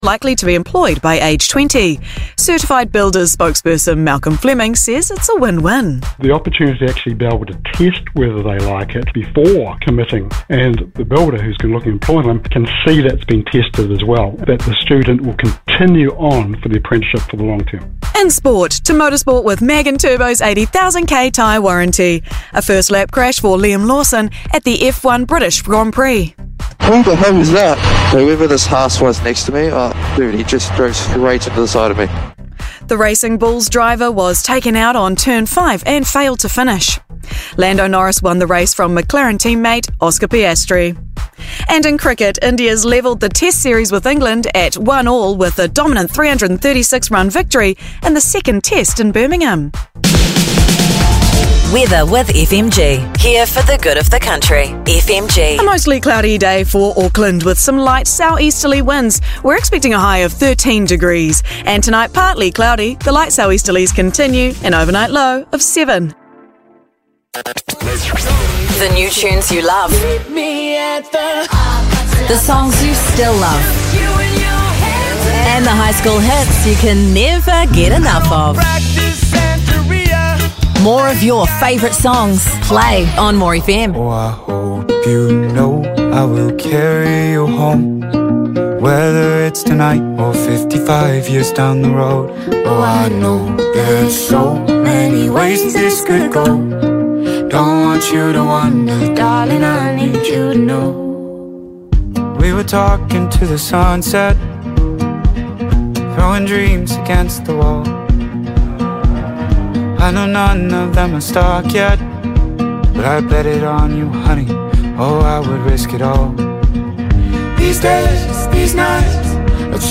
on More FM.